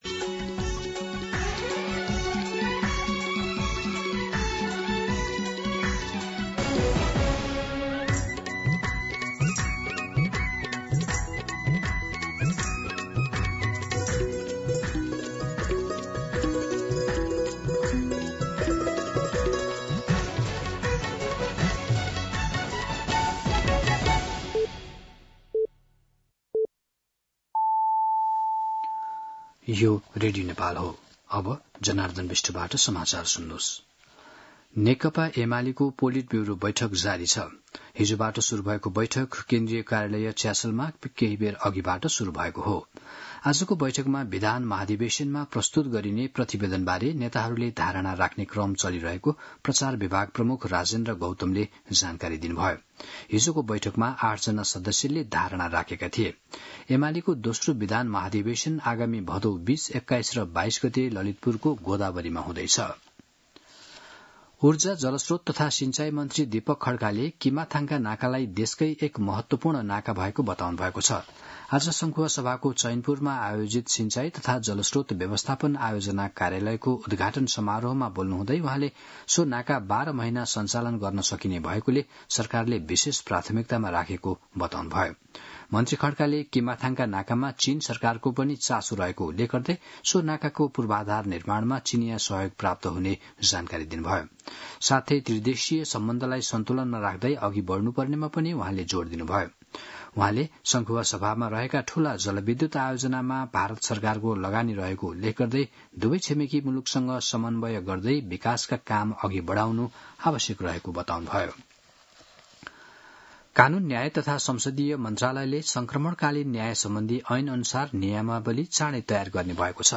दिउँसो १ बजेको नेपाली समाचार : ३ साउन , २०८२
1-pm-Nepali-News-1.mp3